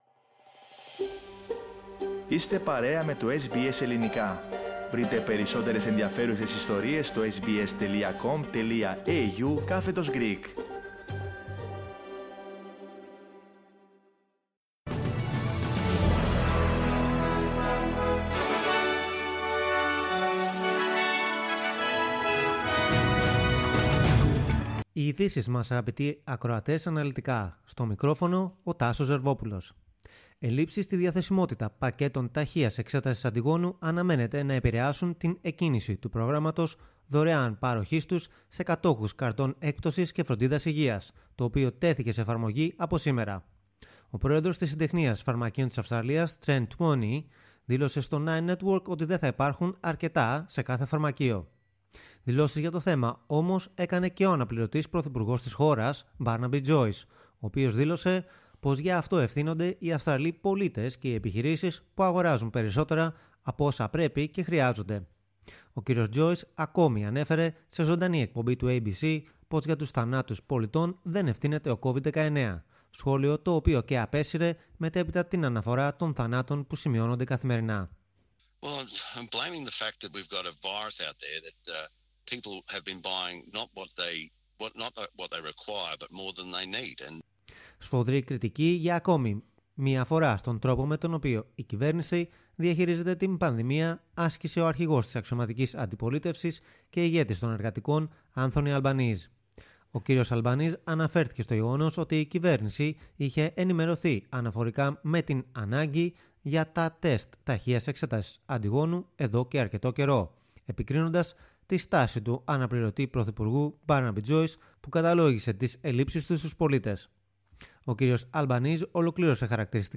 Ειδήσεις στα Ελληνικά - Δευτέρα 24.1.22